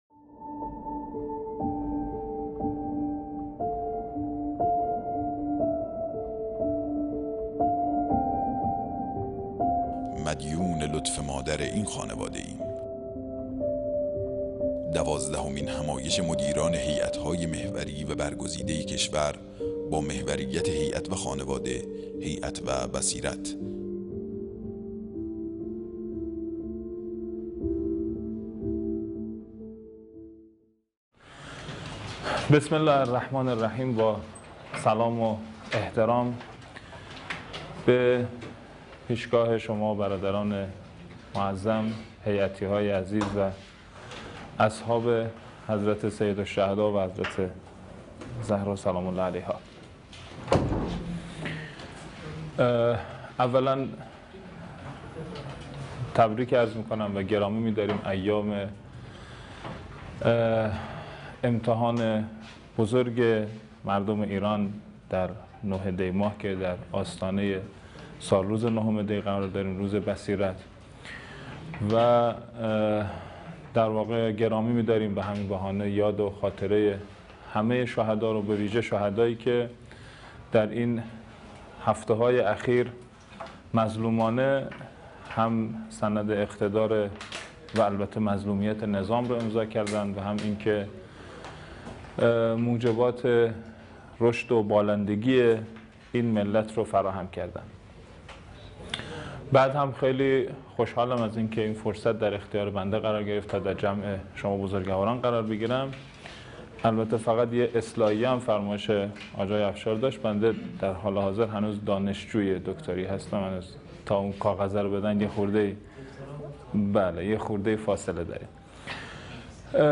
کمیسیون تخصصی
دوازدهمین همایش هیأت‌های محوری و برگزیده کشور با محوریت هیأت و خانواده، هیأت و بصیرت | شهر مقدس قم - مسجد مقدس جمکران